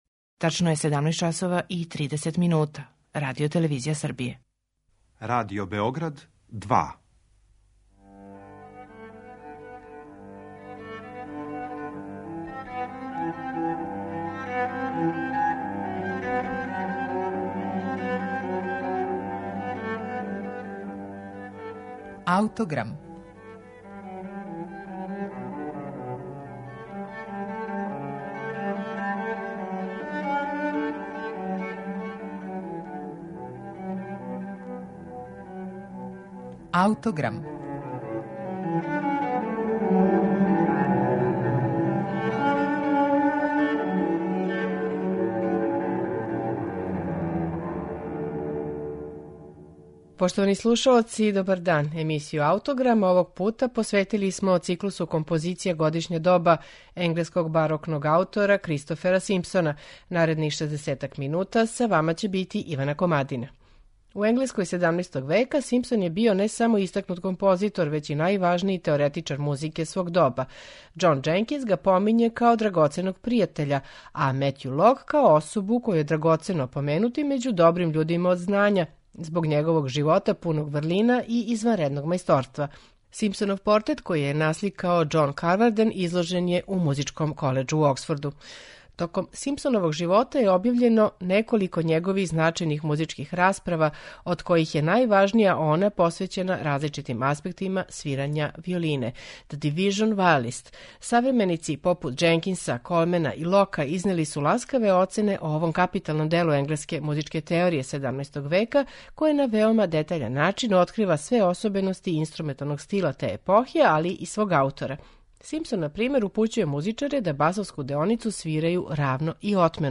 Циклус композиција за анасамбл виола
на оригиналним инструментима Симпсоновог доба